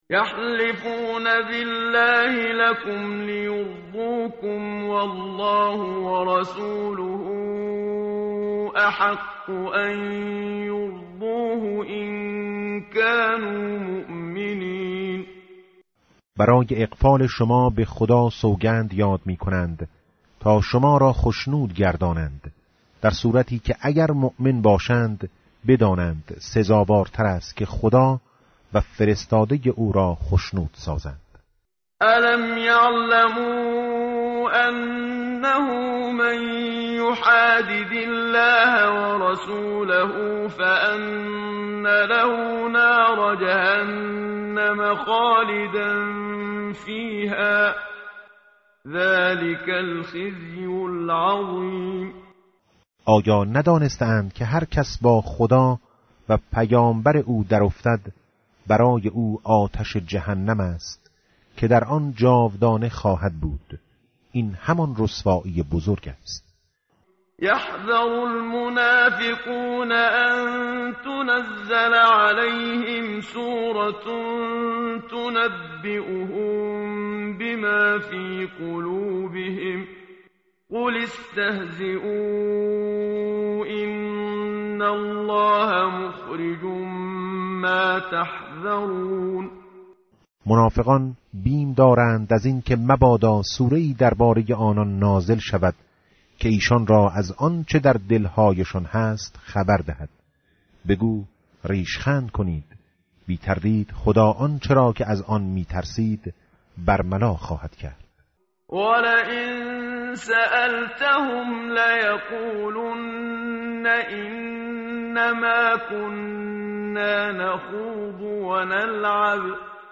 tartil_menshavi va tarjome_Page_197.mp3